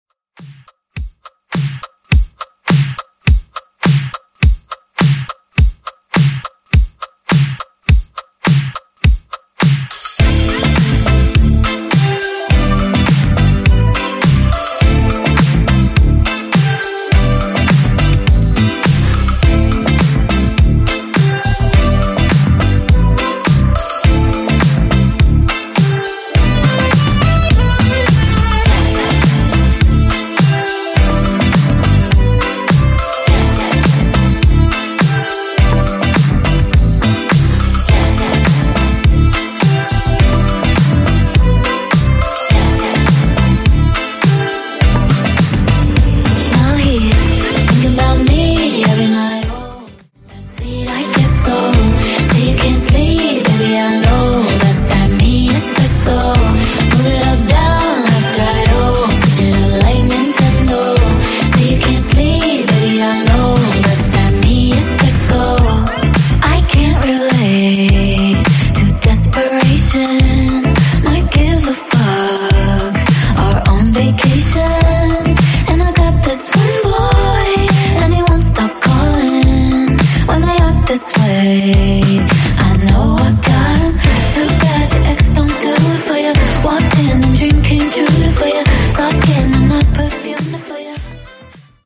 Genre: R & B Version: Clean BPM: 79 Time